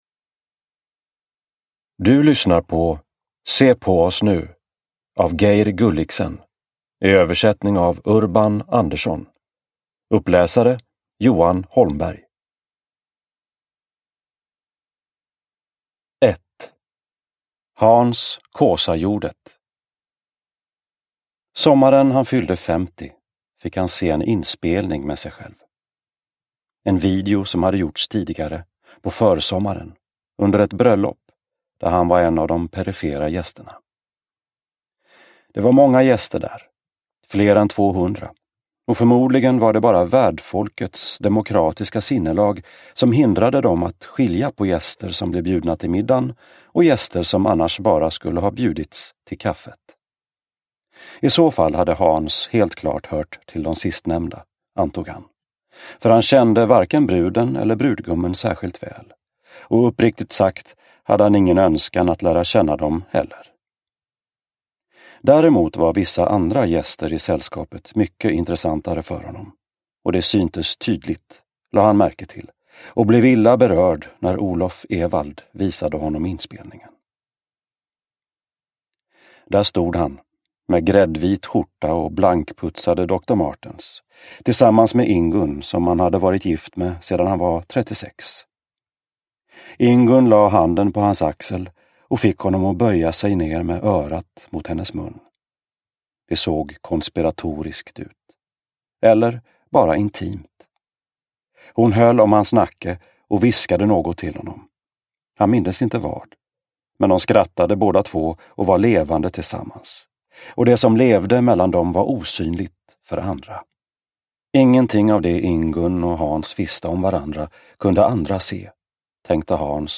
Se på oss nu – Ljudbok – Laddas ner